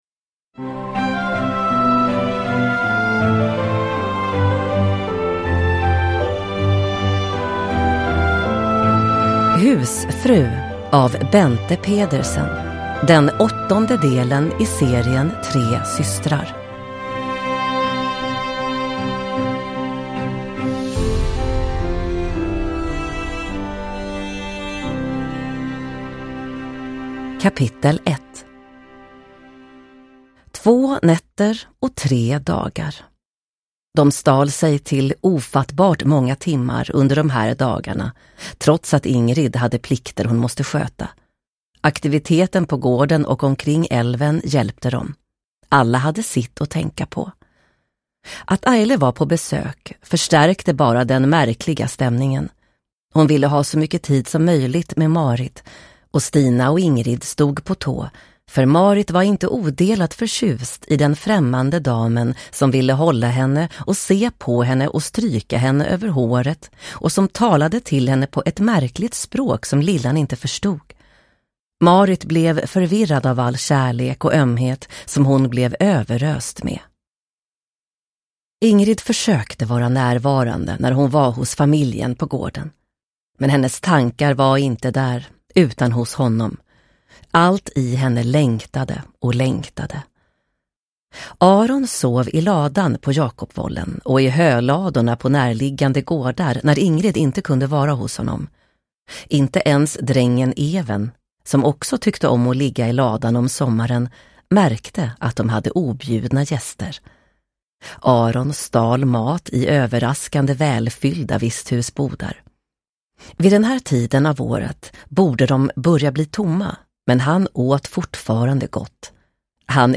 Husfru – Ljudbok – Laddas ner